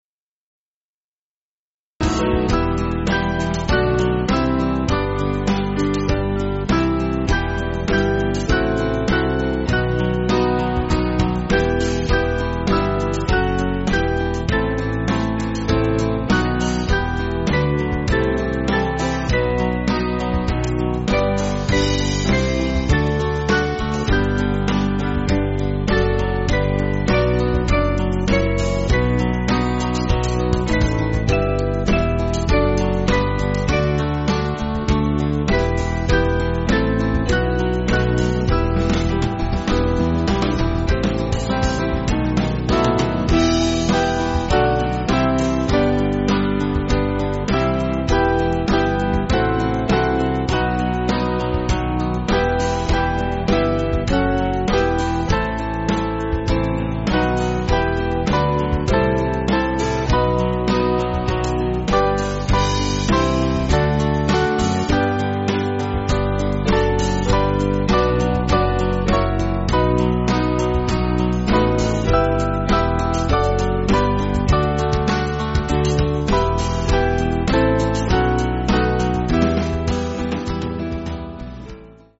Small Band
(CM)   3/Em